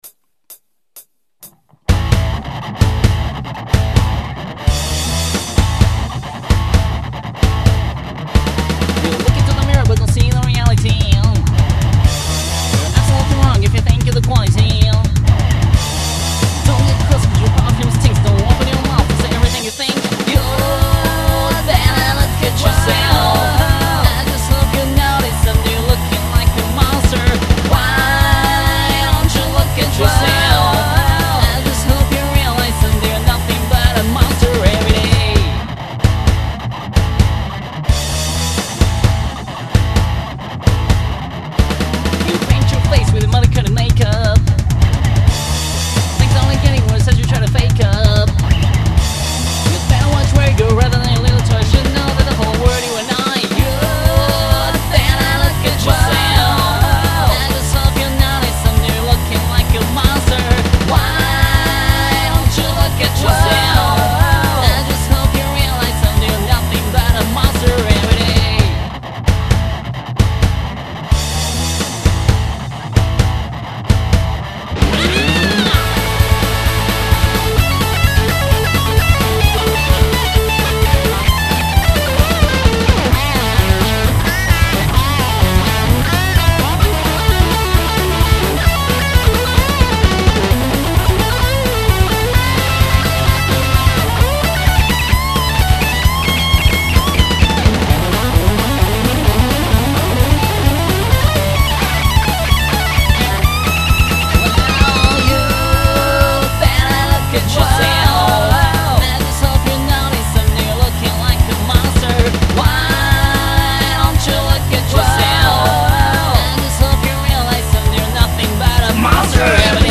ストレートなpunk曲にした。
punk曲でも結局4声使ってしまった。